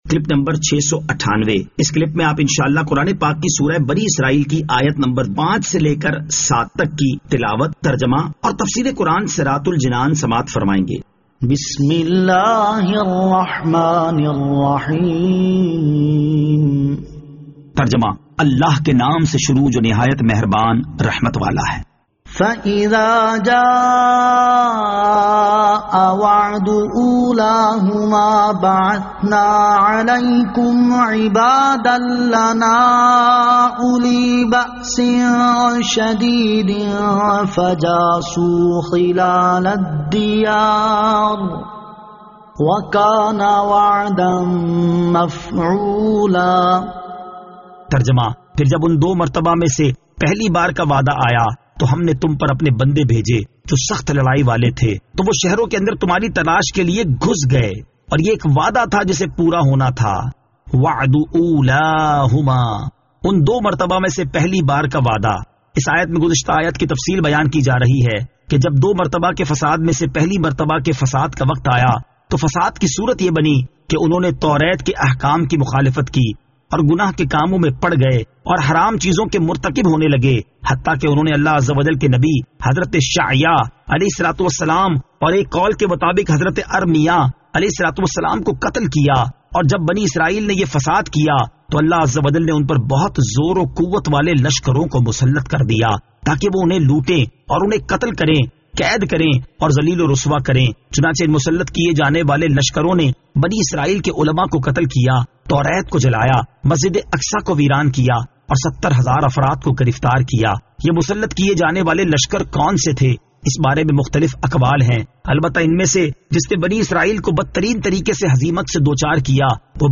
Surah Al-Isra Ayat 05 To 07 Tilawat , Tarjama , Tafseer